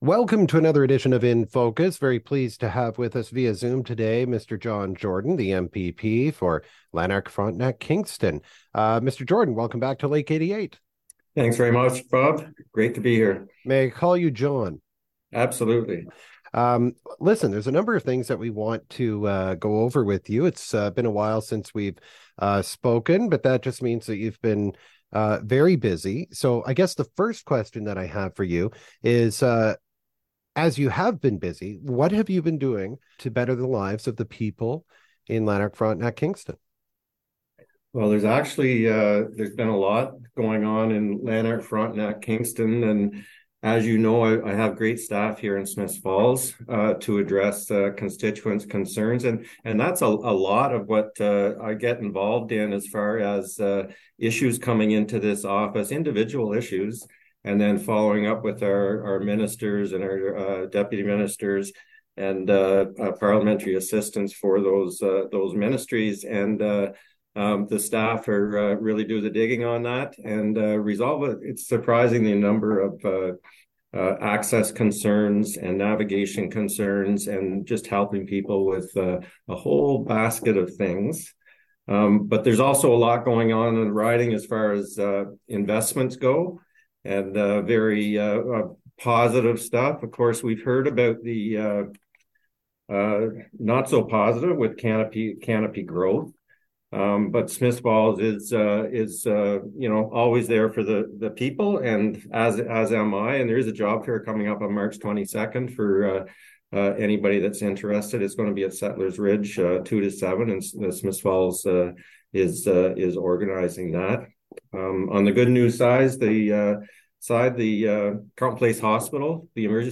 We welcome MPP for Lanark-Frontenac-Kingston John Jordan who offers an update on the work he’s been doing for his constituents of late.